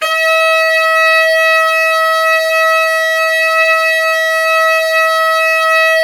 SAX_sfe5x    245.wav